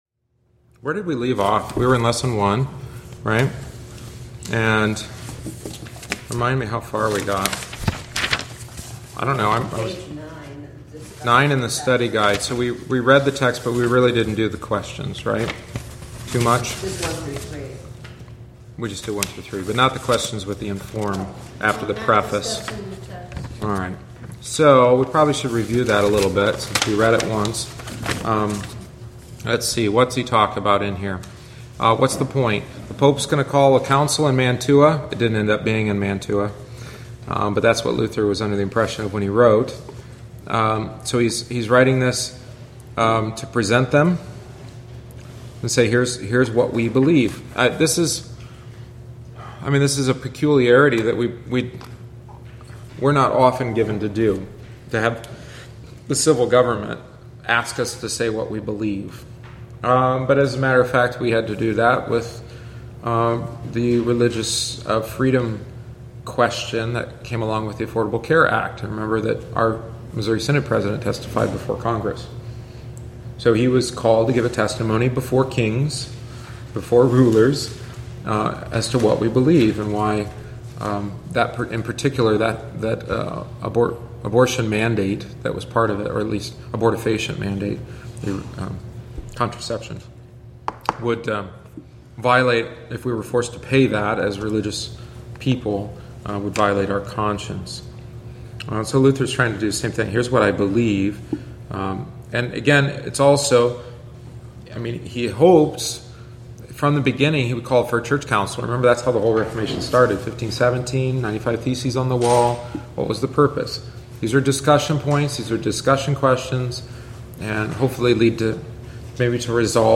Join us each week on Wednesday after Divine Service (~7:45pm) for aÂ study of one of our Lutheran Confessions, the Smalcald Articles. Written by Martin Luther shortly before his death, it is vivid, to the point, and sometimes cantankerous.